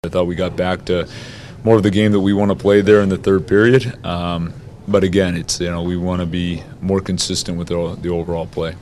Coach Dan Muse says the Penguins faltered in the second period and it cost them.